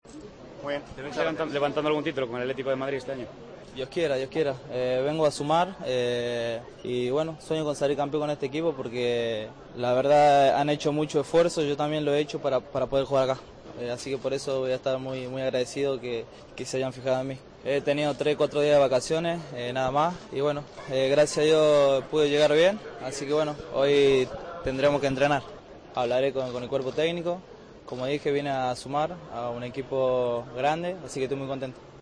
El argentino habló con los medios a su llegada a Barajas. Kranevitter se mostró muy "contento" por firmar por el Atlético y confirmó que este mismo martes trabajará a las órdenes de Simeone.